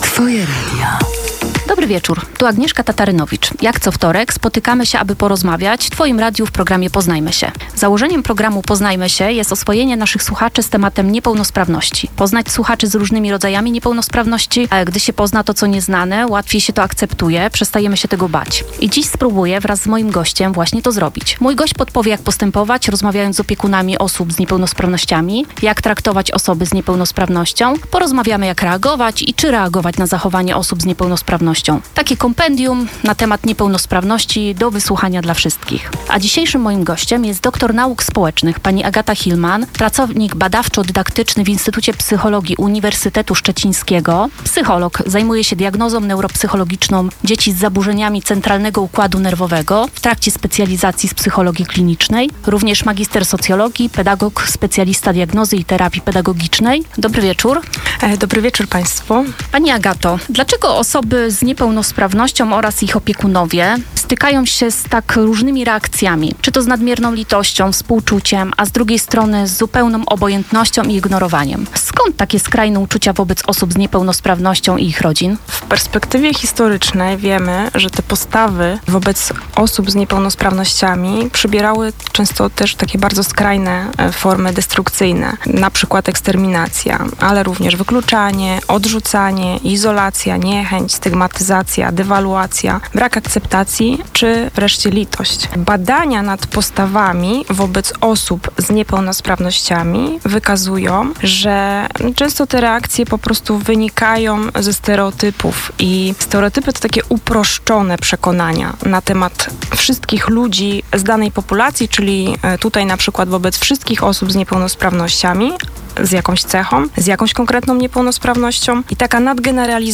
Podpowie, jak postępować rozmawiając z opiekunami osób z niepełnosprawnością, jak traktować i jak reagować na zachowanie osób z niepełnosprawnością. Rozmowa na temat niepełnosprawności do wysłuchania dla wszystkich.